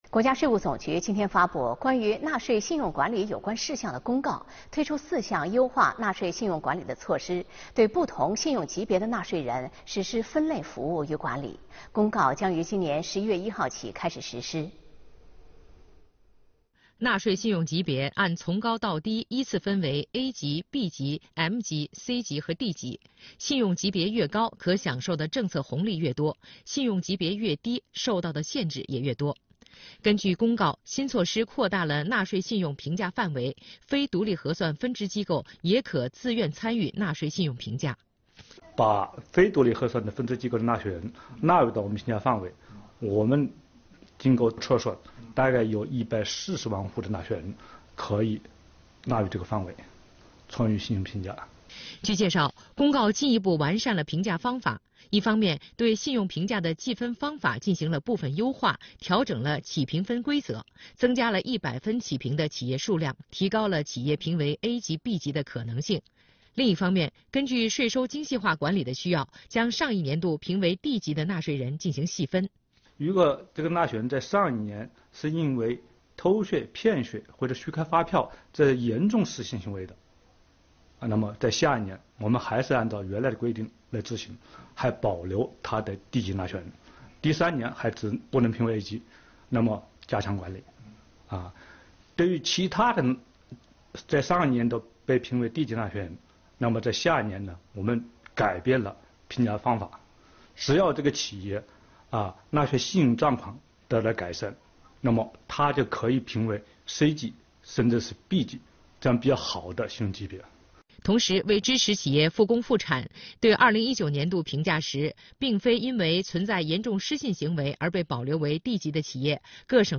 视频来源：央视《新闻直播间》